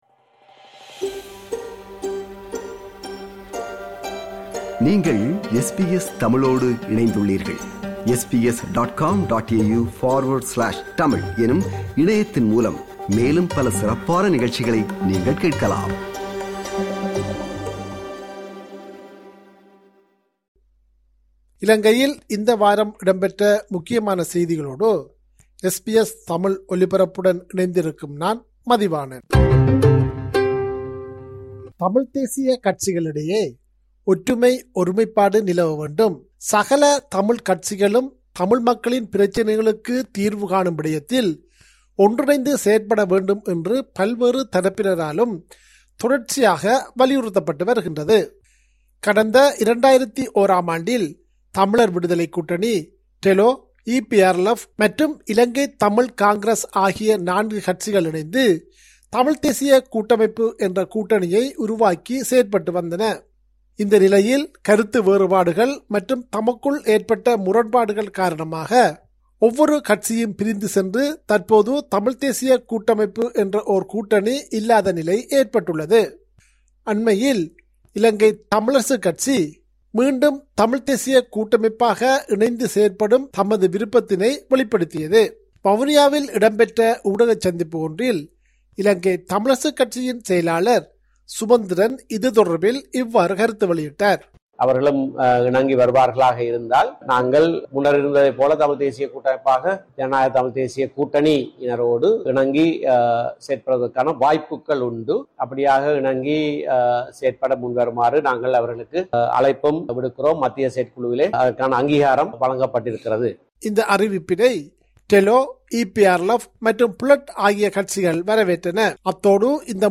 இலங்கை: இந்த வார முக்கிய செய்திகள்